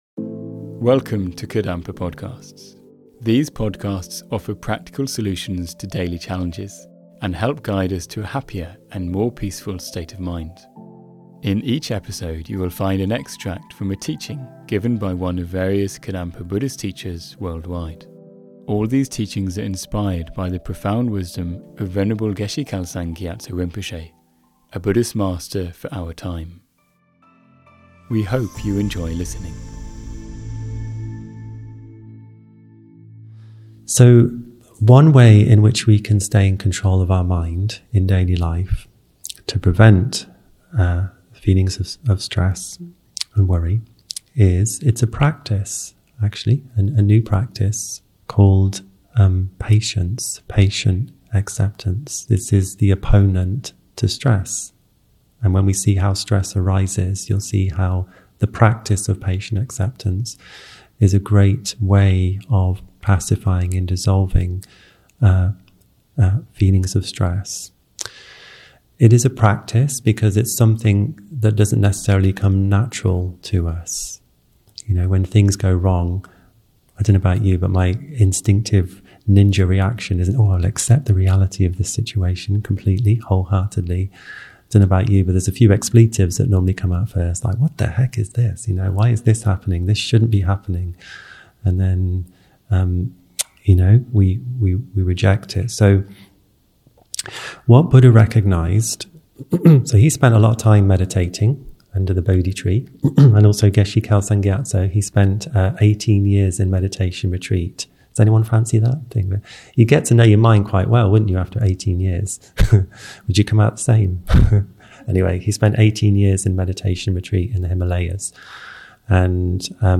We see how by accepting difficulties in our life we come to experience joy and peace. This podcast is an extract of a talk given at KMC Canada based on the book How to Solve our Human Problems by Geshe Kelsang Gyatso